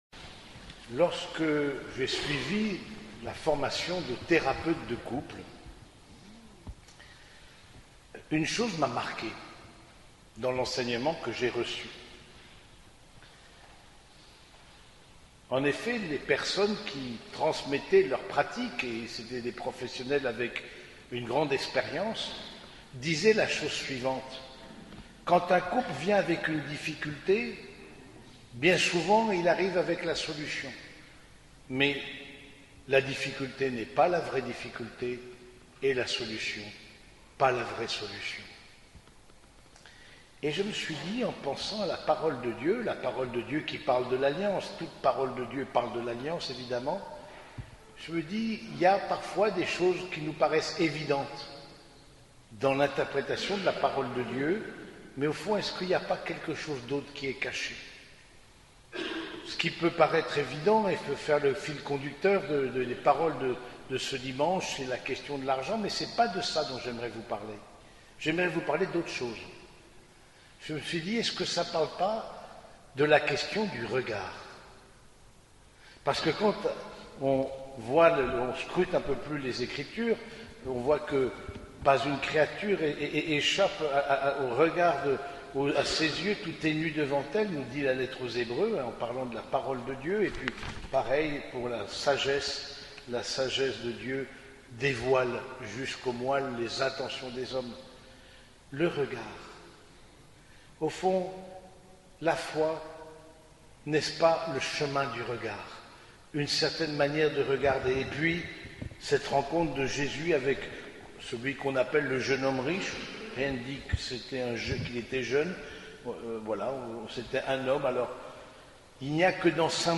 Homélie du 28e dimanche du Temps Ordinaire